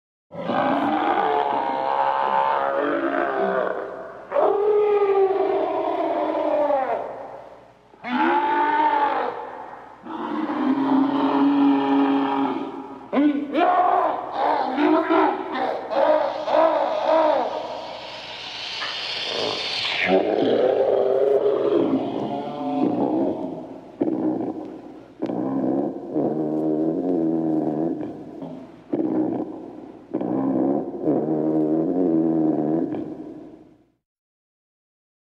Рычание Лизуна из Охотников за привидениями